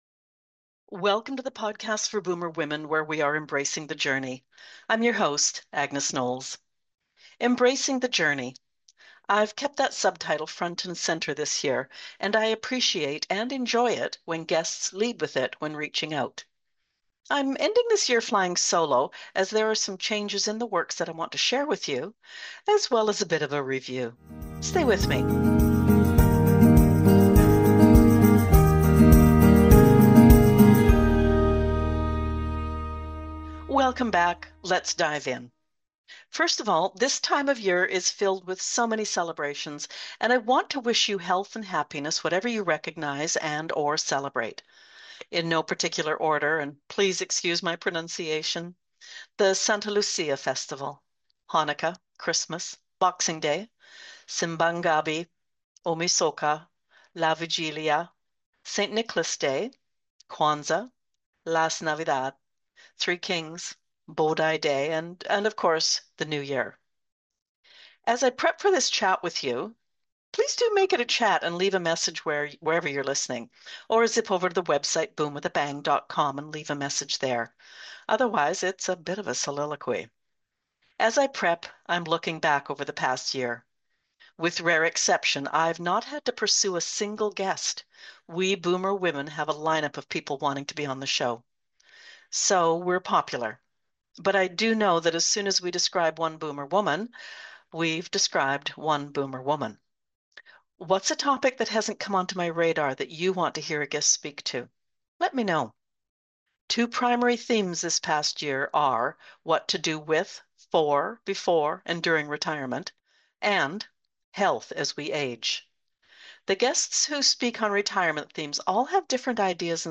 I’m flying solo this week so I can share some news and a few changes coming in 2026 with you.